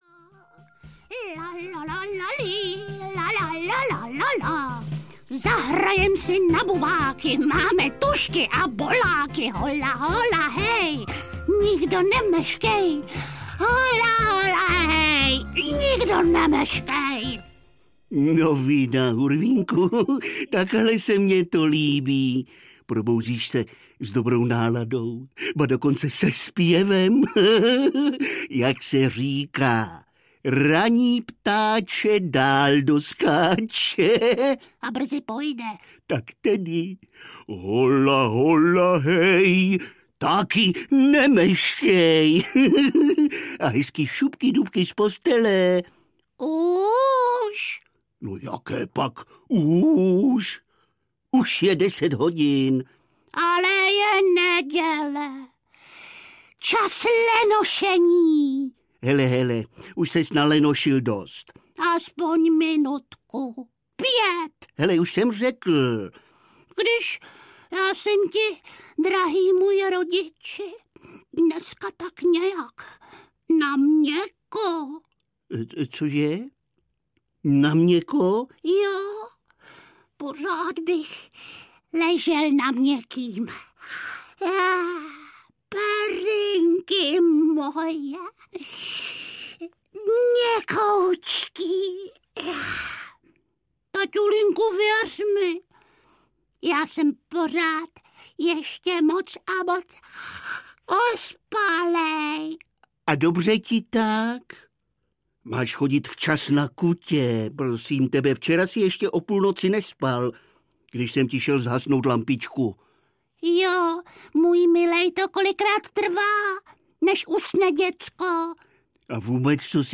Hurvínkovy všetečné otázky audiokniha
Ukázka z knihy